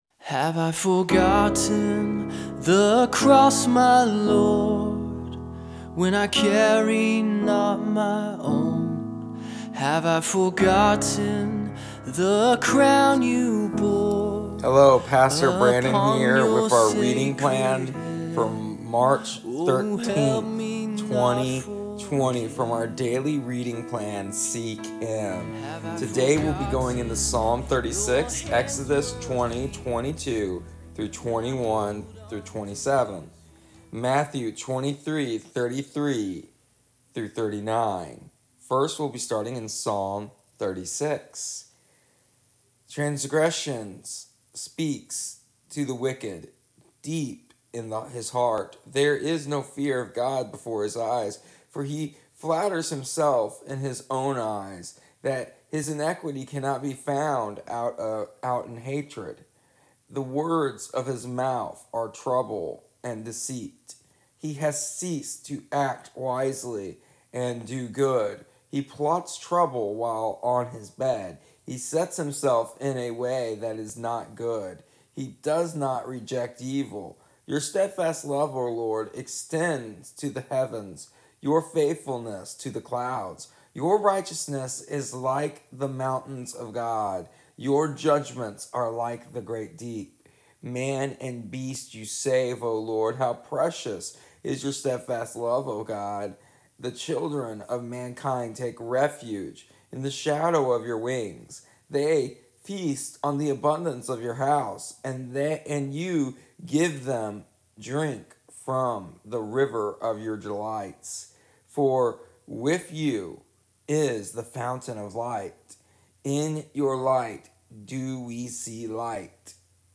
Here is our daily audio readings for March 13th, 2020. I am feeling a bit under the weather today and had to just keep it at the readings.